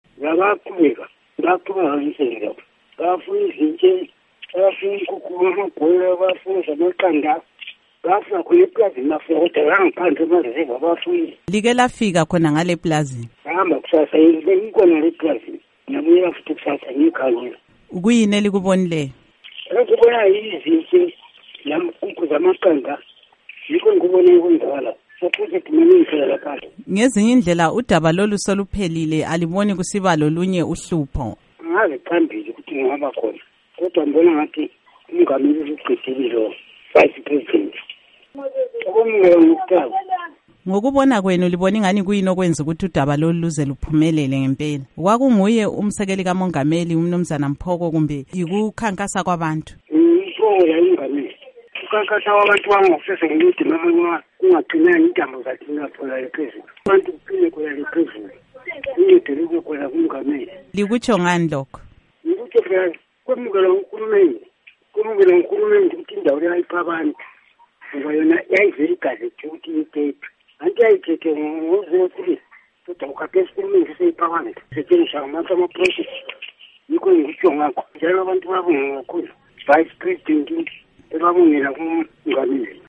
Inxoxo leNduna uMasuku weMatopo